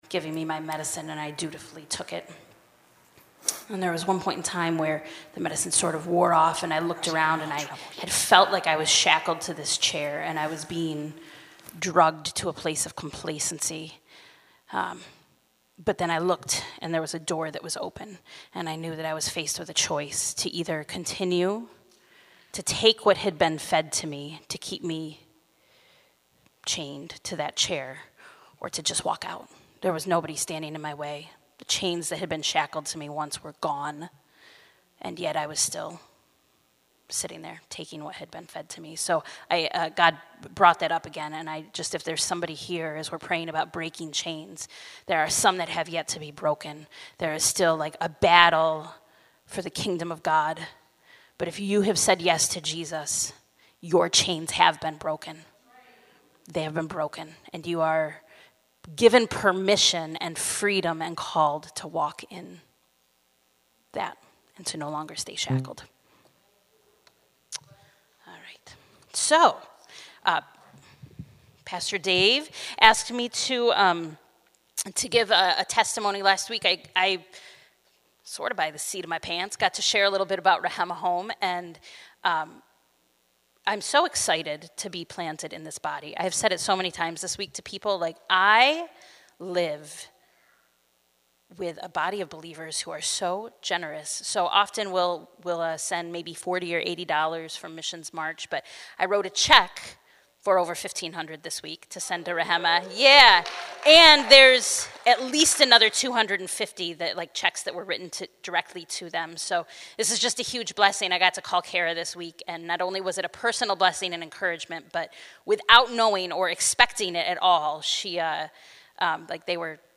All Sermons Longsuffering-Fruit of the Spirit Series May 23